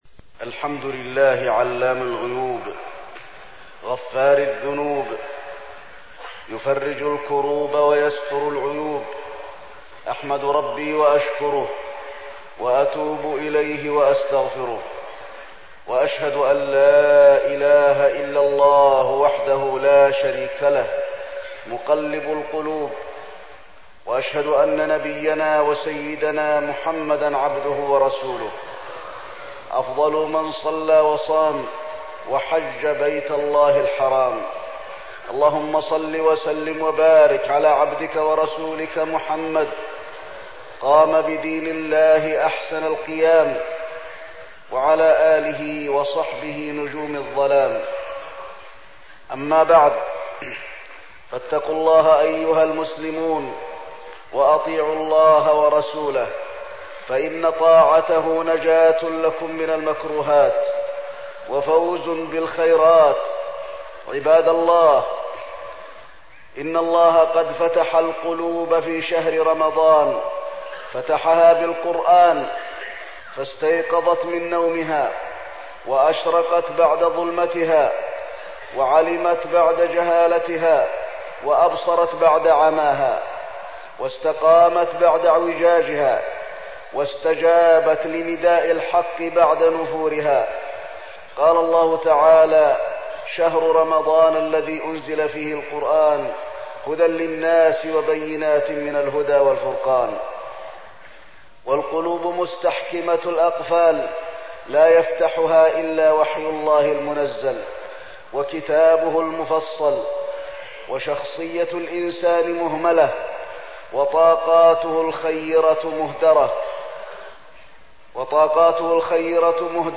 تاريخ النشر ٢٢ رمضان ١٤١٧ هـ المكان: المسجد النبوي الشيخ: فضيلة الشيخ د. علي بن عبدالرحمن الحذيفي فضيلة الشيخ د. علي بن عبدالرحمن الحذيفي اغتنام شهر رمضان